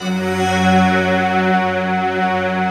SI1 CHIME06L.wav